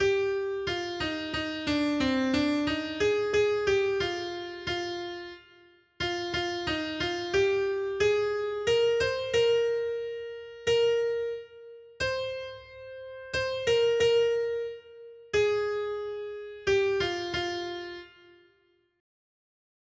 Noty Štítky, zpěvníky ol99.pdf responsoriální žalm Žaltář (Olejník) 99 Ž 46, 2-3 Ž 46, 5-6 Ž 46, 8-9 Skrýt akordy R: Proudy bystřin jsou k radosti Božímu městu, přesvatému stánku Nejvyššího. 1.